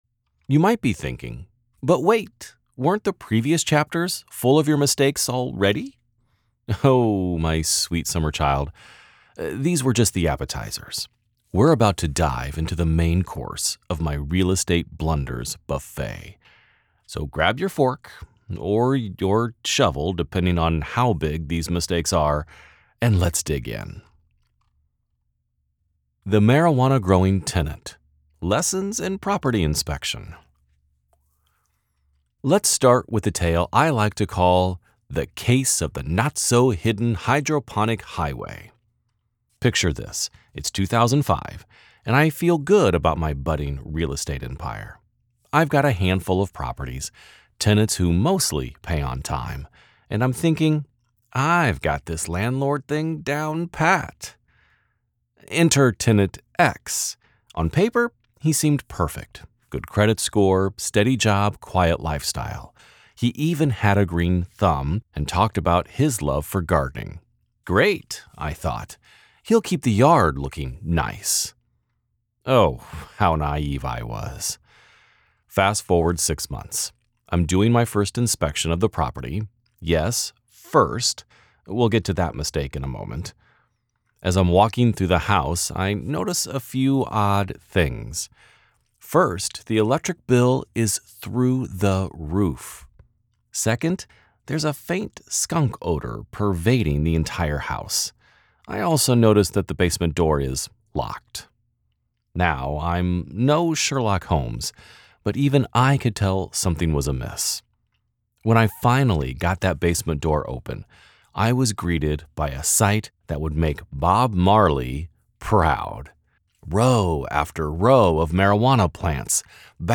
Male
English (North American)
Adult (30-50)
Natural-Friendly-Confident-Informative
Audiobook - Nonfiction
0321Non_Fiction_-_Business_-_Comedic.mp3